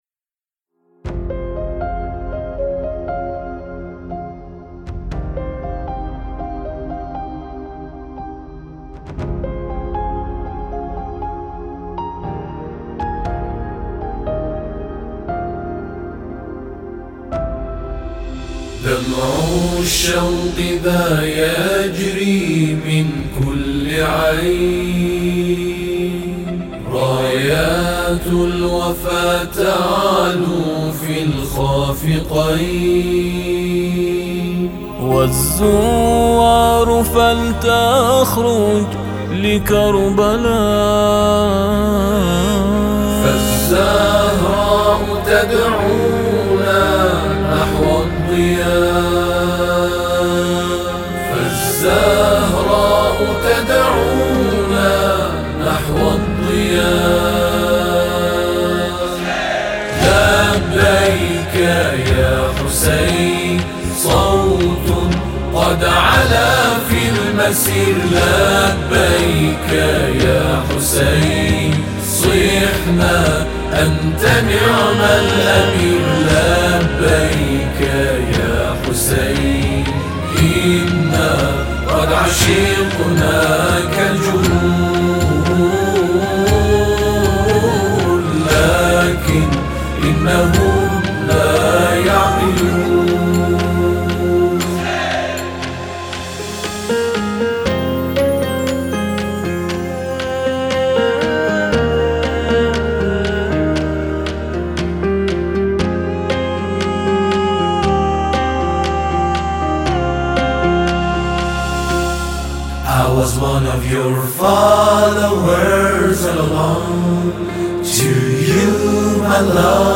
خوانندگان میهمان